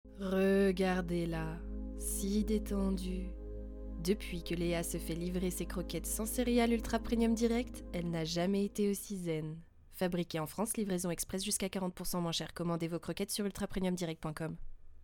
Voix Publicité